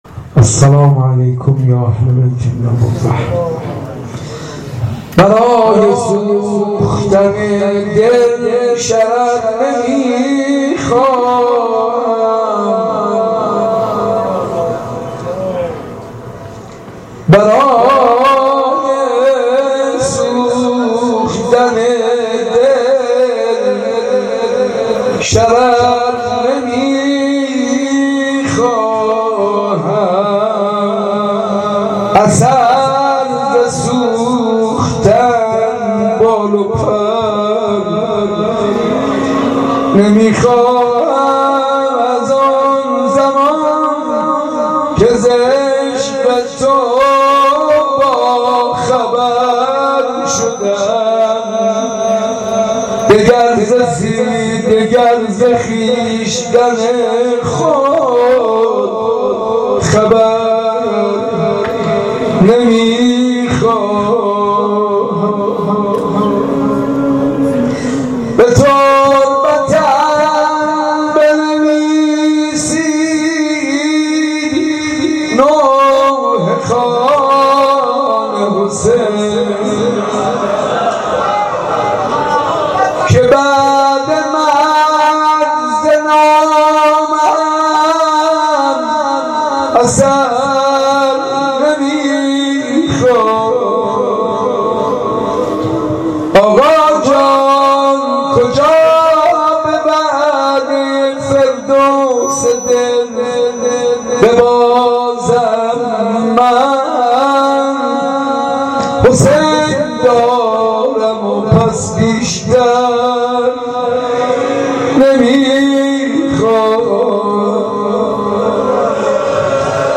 عقیق: اولین شب از مراسم عزاداری دهه سوم محرم با حضور جمع زیادی از دلدادگان حضرت سیدالشهدا (ع) در بیت الزهرا(س) برگزار شد.